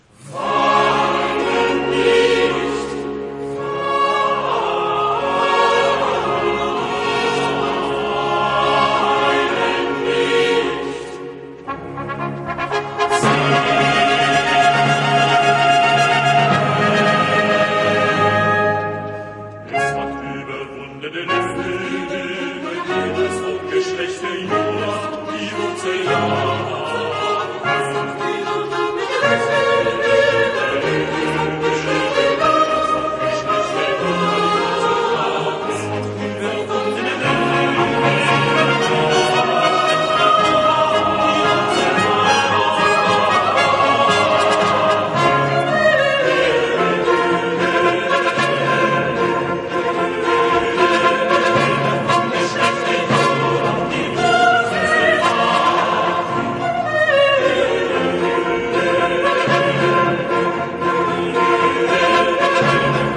Chor